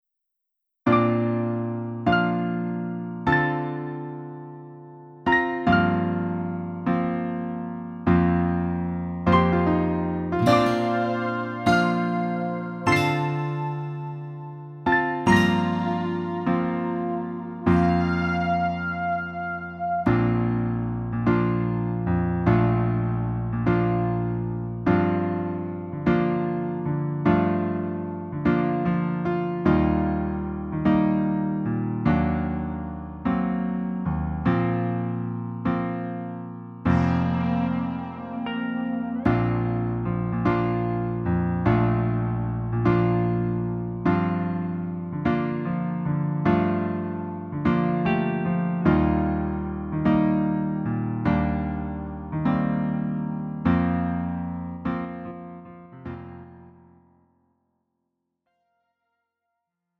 음정 원키 4:23
장르 가요 구분 Lite MR